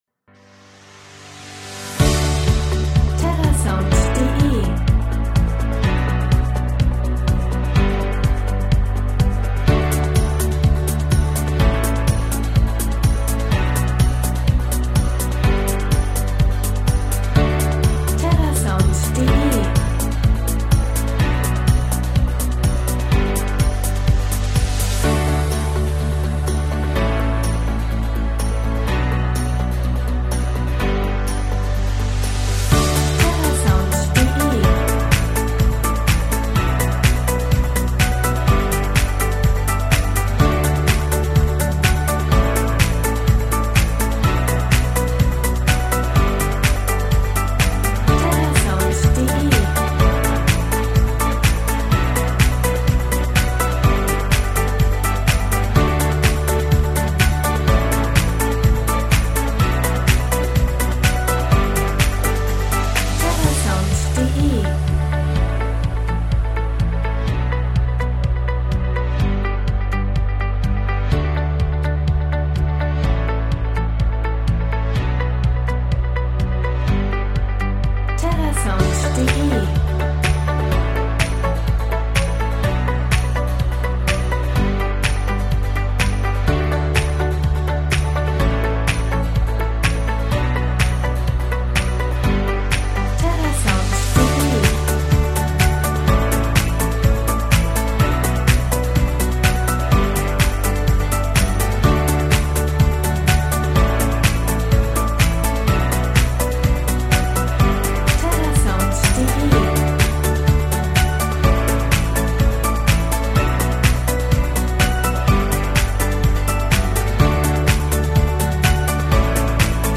Tempo: 125 bpm